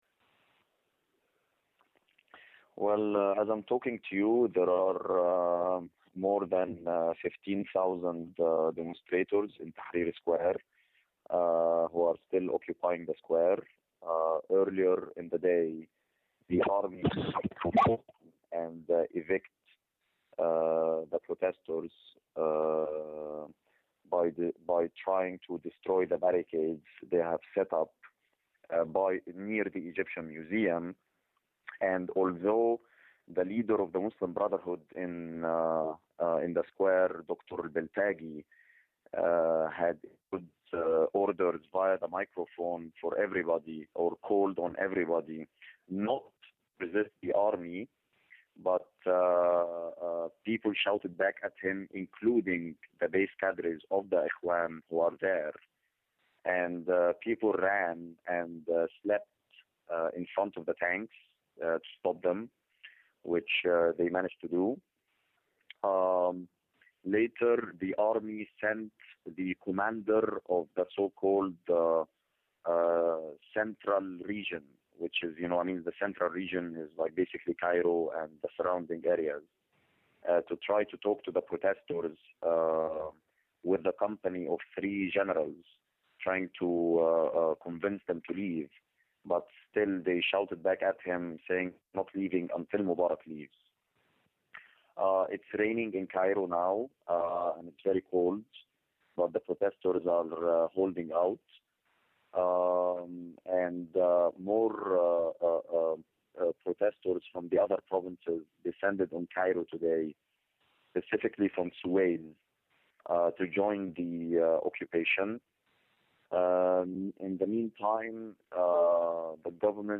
This interview with Egyptian revolutionary socialist journalist Hossam el-Hamalawy was conducted on Saturday, February 5th at 8pm (Egyptian time).